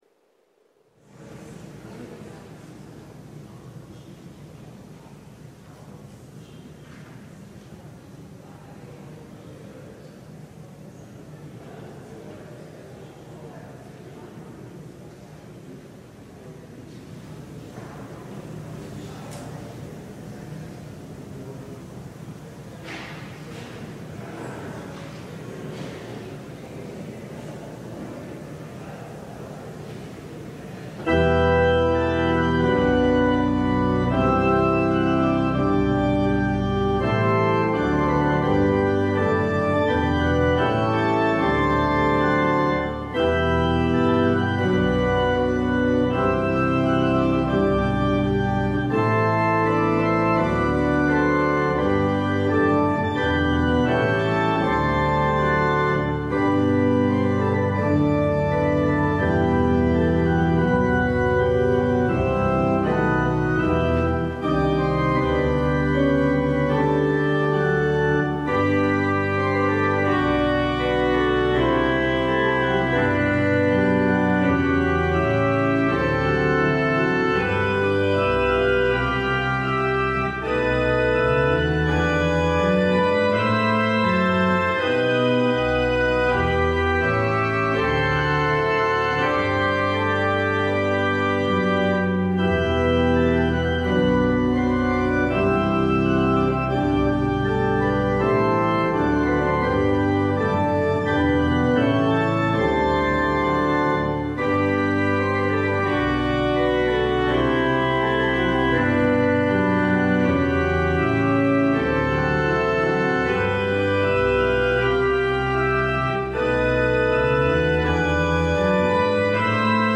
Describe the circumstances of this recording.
LIVE Morning Worship Service - The Imperfect Anointed: Saul, David and the Acid of Envy